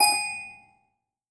Toy Piano Key (G#)
bell box chime cute ding doll dollhouse house sound effect free sound royalty free Music